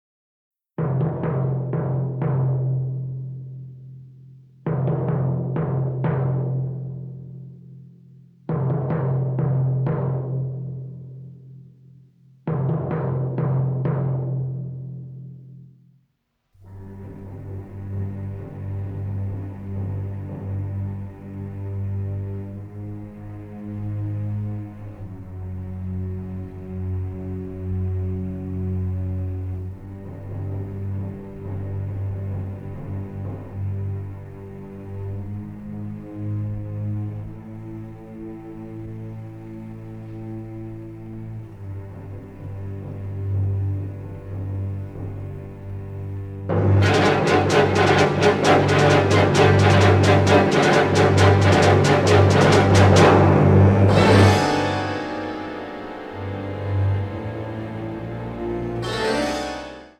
suspense writing for strings